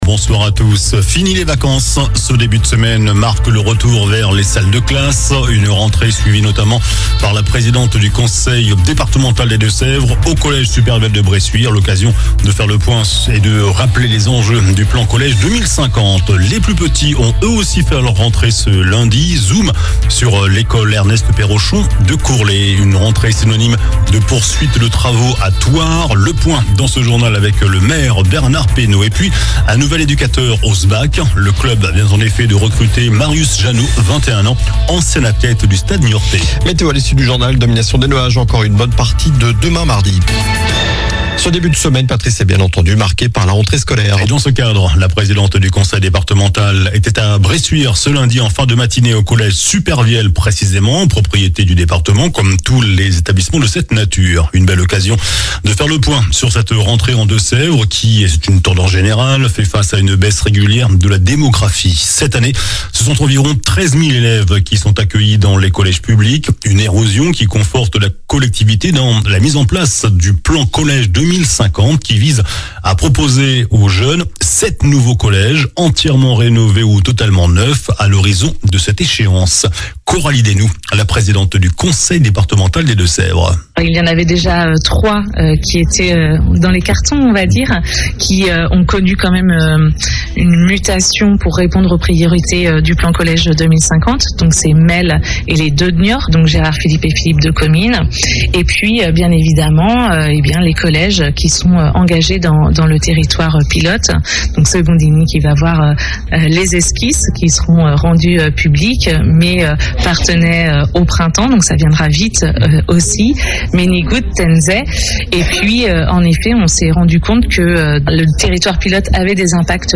JOURNAL DU LUNDI 02 SEPTEMBRE ( SOIR )